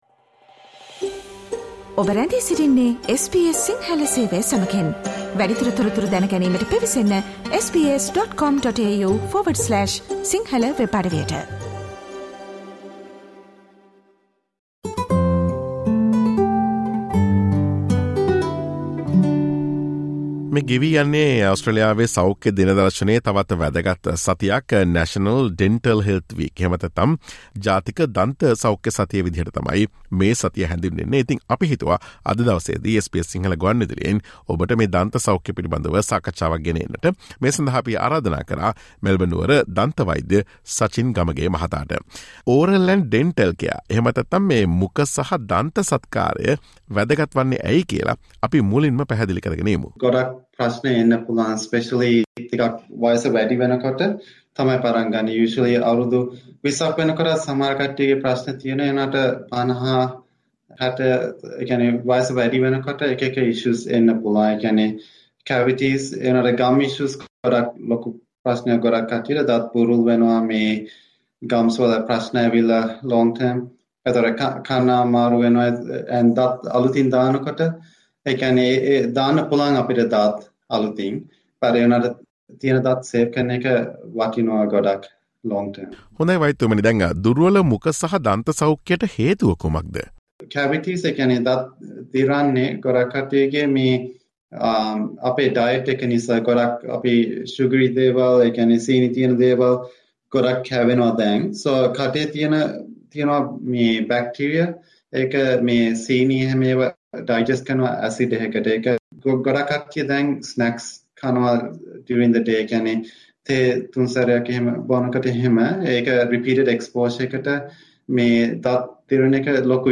Listen to SBS Sinhala Radio's interview on main dental diseases in Australia and their treatments.